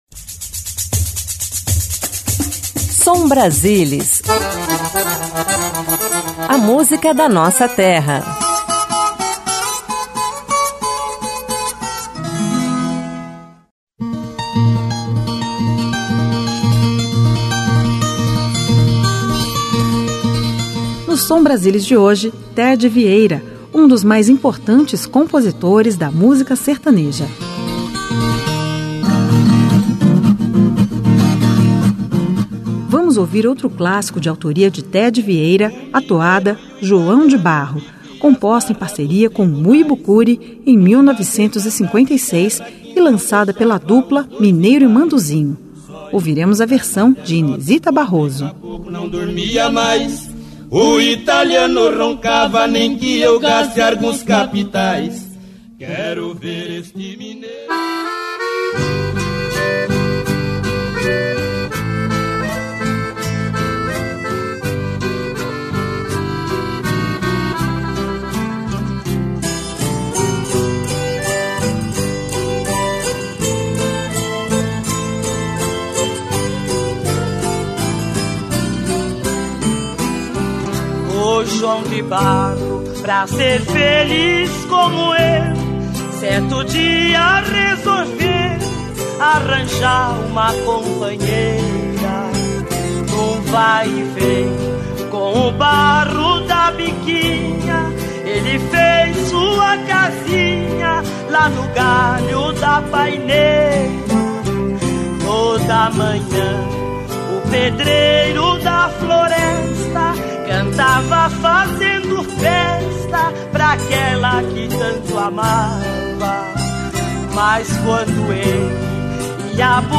Moda de viola Sertanejo Viola caipira Cururu Toada